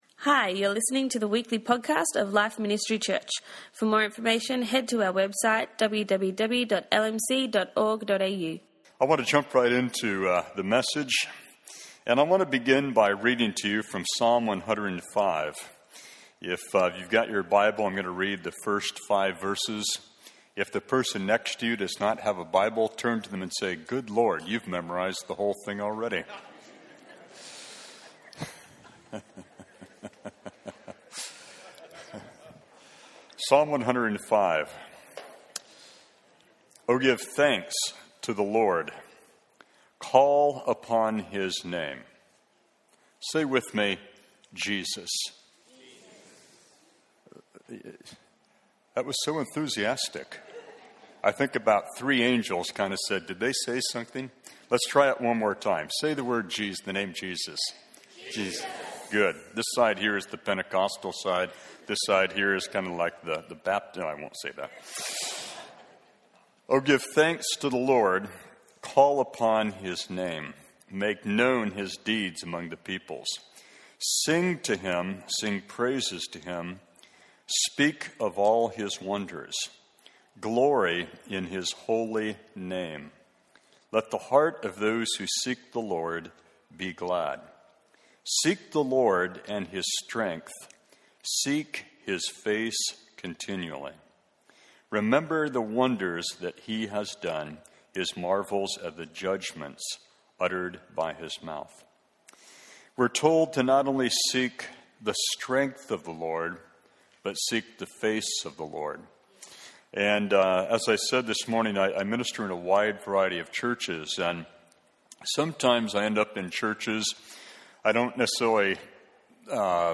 preached on the Holy Spirit and gave opportunity for response for healing!